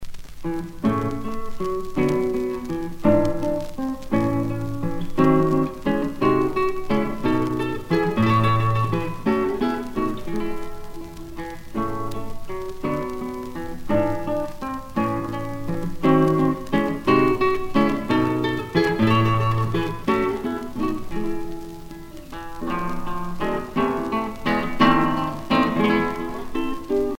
danse : menuet
Pièce musicale éditée